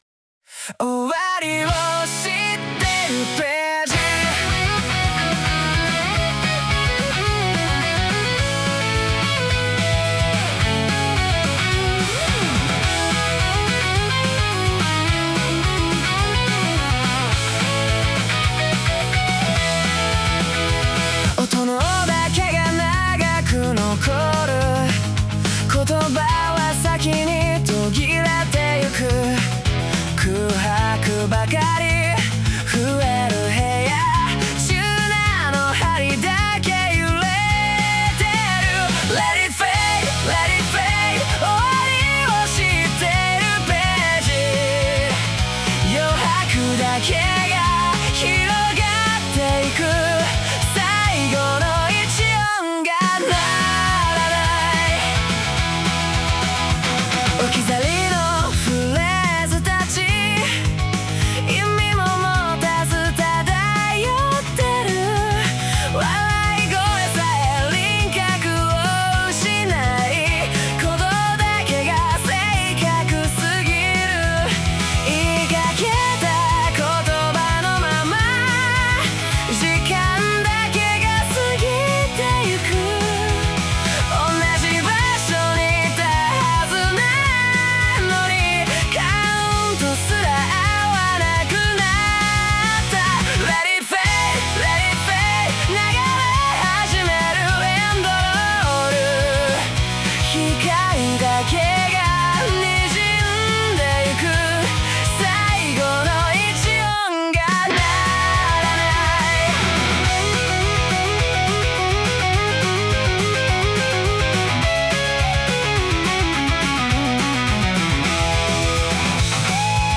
男性ボーカル